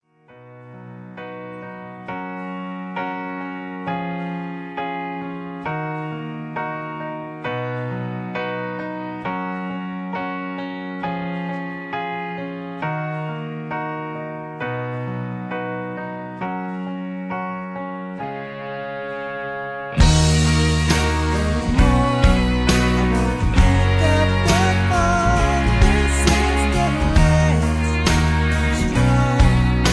Key-Gb) Karaoke MP3 Backing Tracks